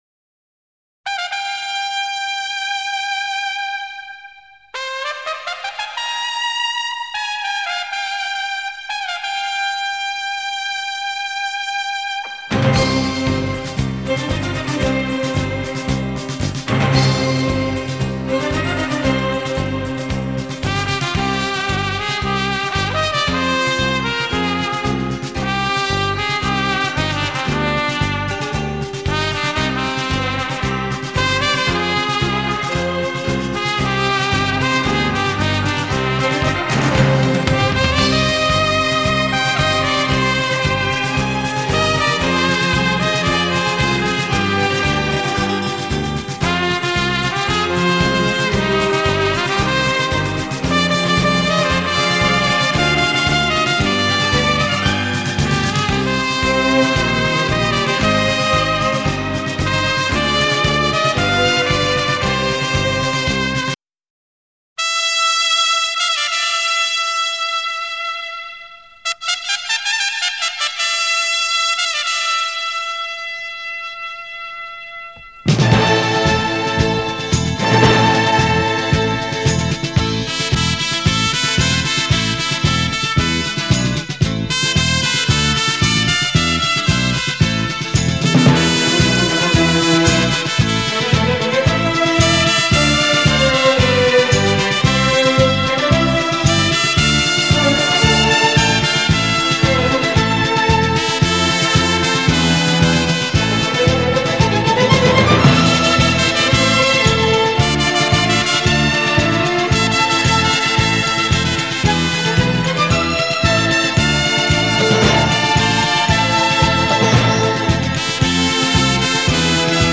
これをアップテンポに編集したのが屋敷の中に入る時の音楽ですね。冒頭のテンテテンテテンというリズムのとき屋敷に侵入。